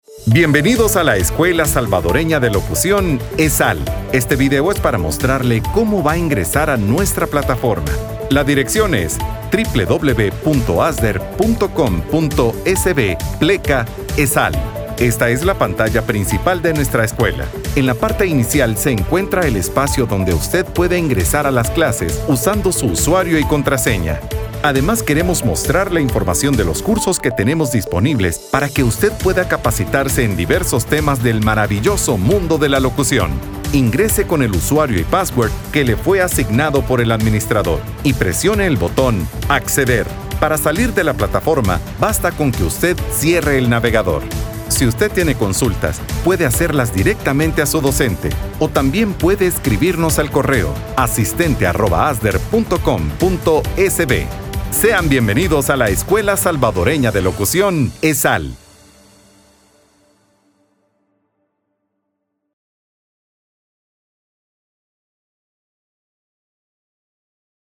Sprechprobe: eLearning (Muttersprache):
Demo E-learning_0.mp3